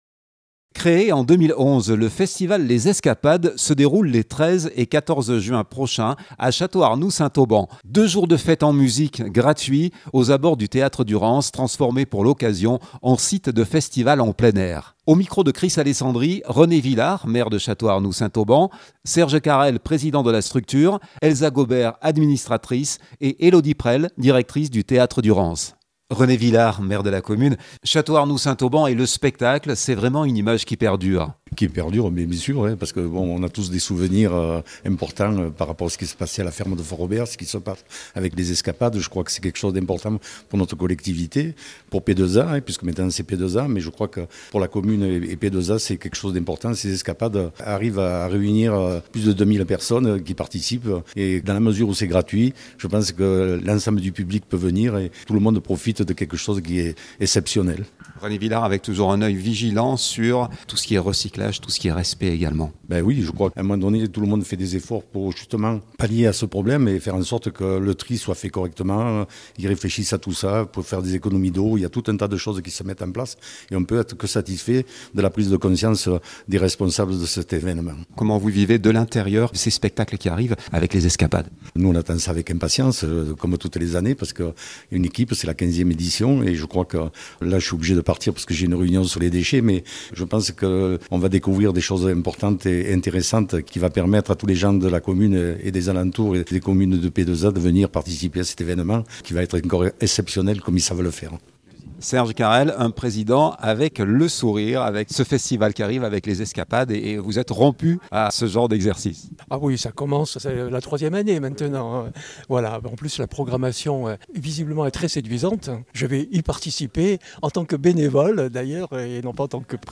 René Villard Maire de Château-Arnoux Saint Auban